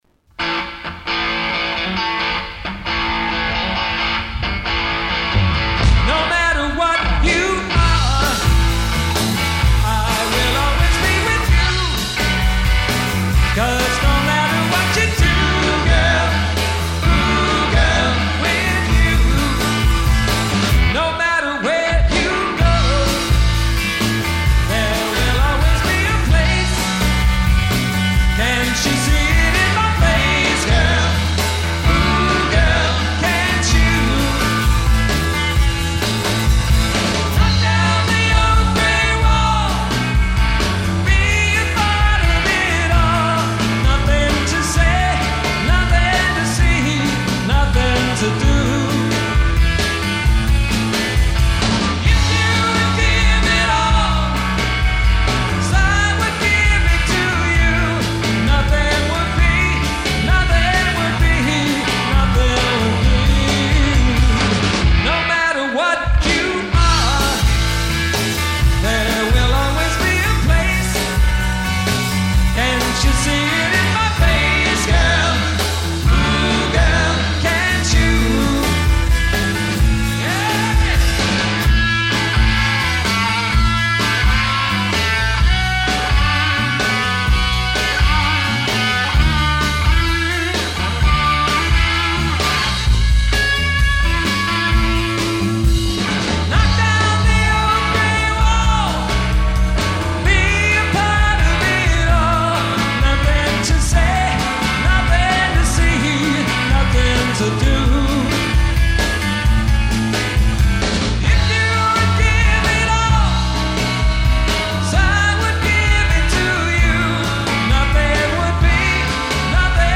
Live Music Samples
LIVE at The Maryvale Great Adventure - Phoenix, Arizona